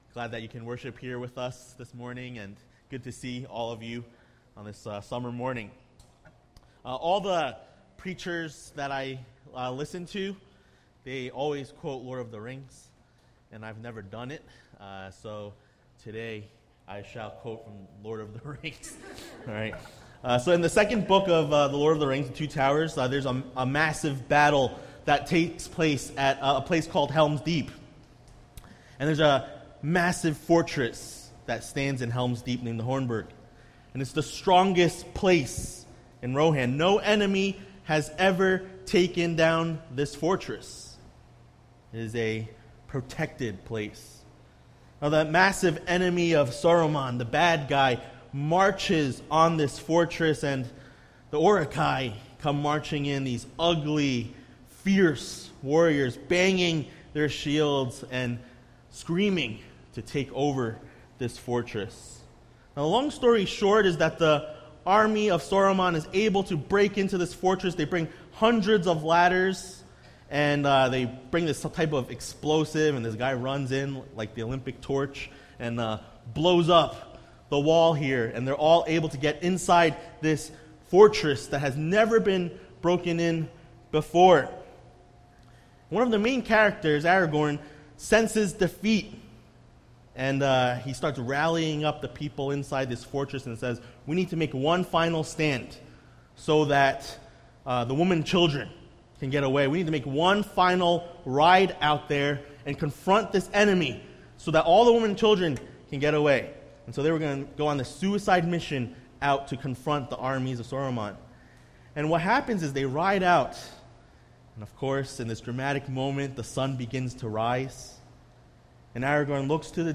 A message from the series "General Topics."